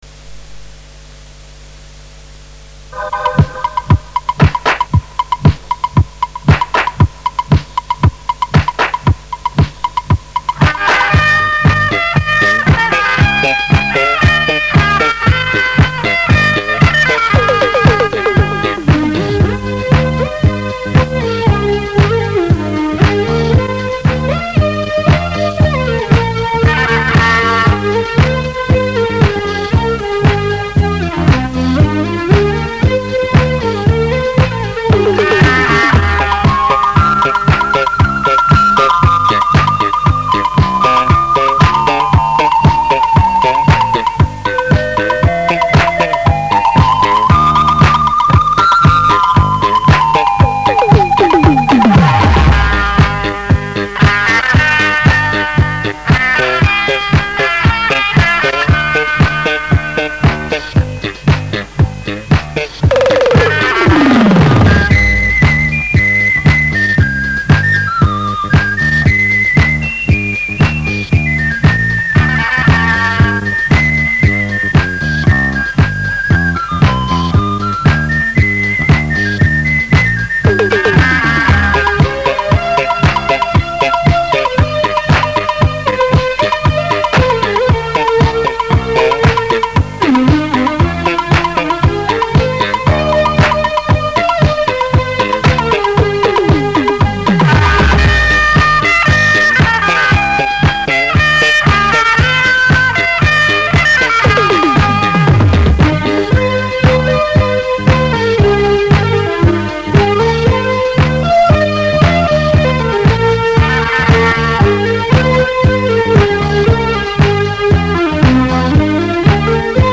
现将磁带翻录成MP3,选其中一首上传，只是音质差强人意，希望有听过的朋友提供磁带名称等详细信息，或有此光盘的朋友上传。
不过不是祝瑞莲《跳动72》，我的是纯器乐，没有人声。